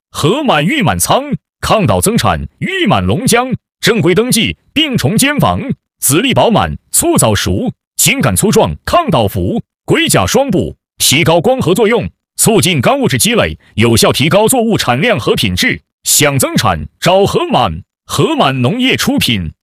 男声配音员 更多+
• 男-060号